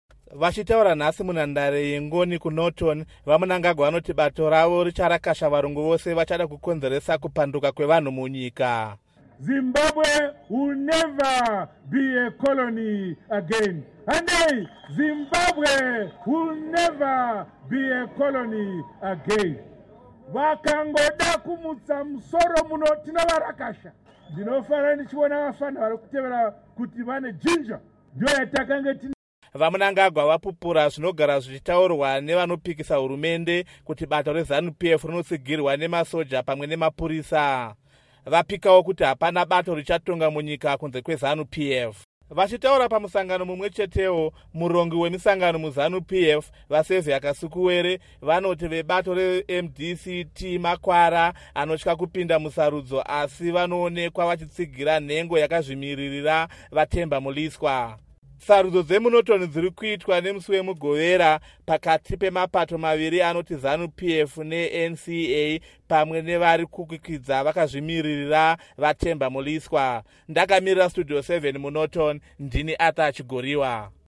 Vachitaura nemusi weChina munhandare yeNgoni kuNorton, VaMnangagwa vanoti bato ravo richarakasha varungu vose vachada kukonzeresa kupanduka kwevanhu munyika.